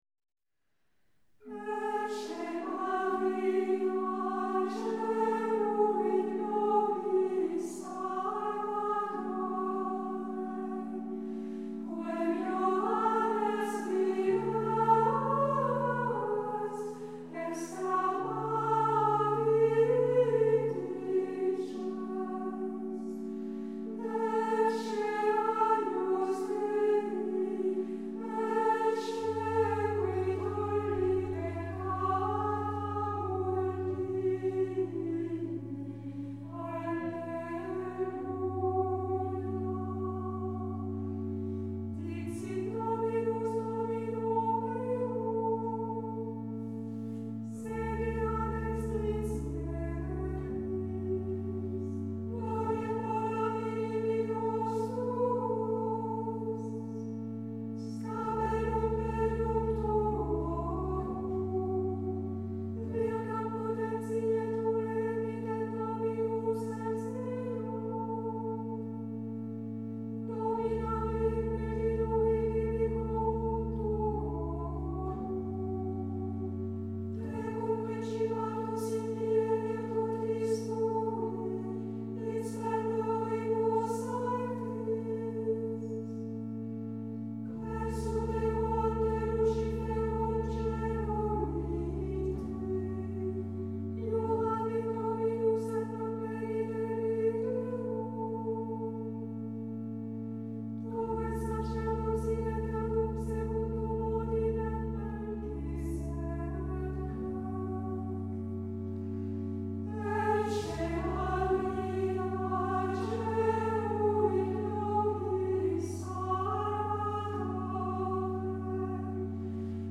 05-Antiphona-Ecce-Maria-Ps.-10.mp3